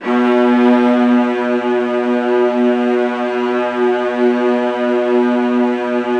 VIOLAS C#3-R.wav